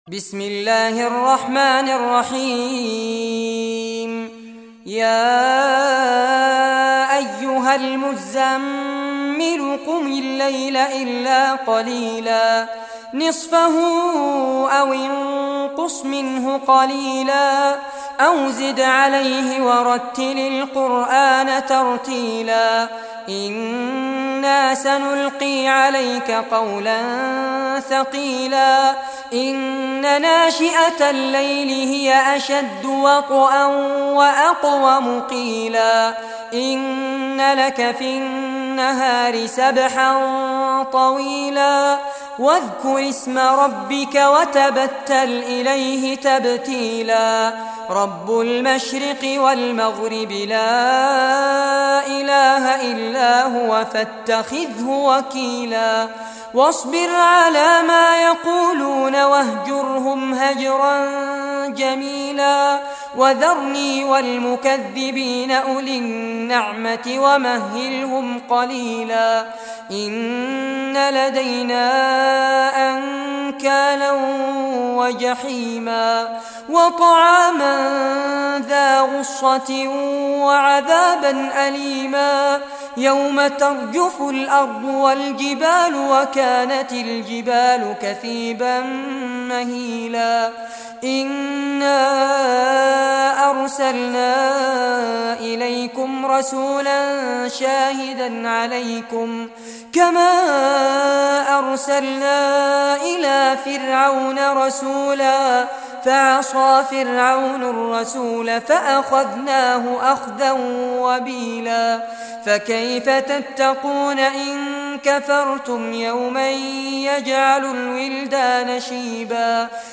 Surah Muzammil Recitation by Fares Abbad
Surah Muzammil, listen or play online mp3 tilawat / recitation in Arabic in the beautiful voice of Sheikh Fares Abbad.